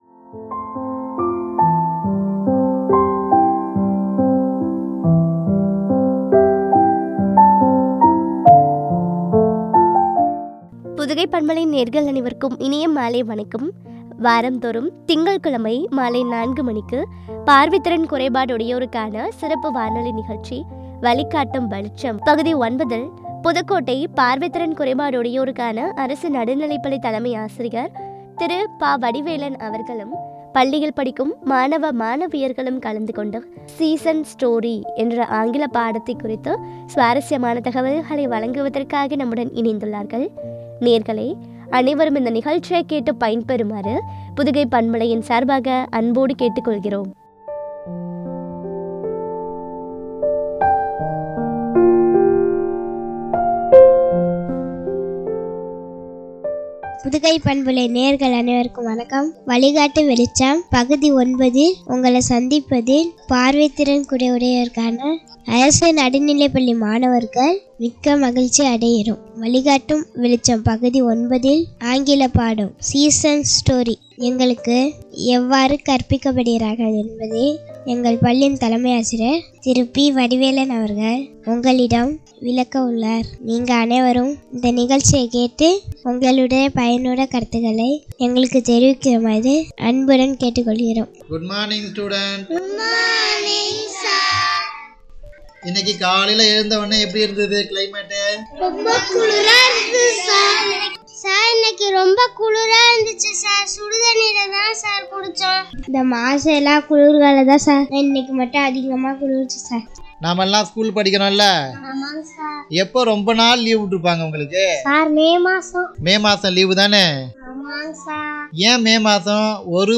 ஆங்கில பாடம், ” Season’s Story” குறித்து வழங்கிய உரையாடல்.